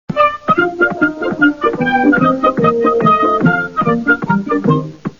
Сборник звуков из мультфильма
Радостная заставка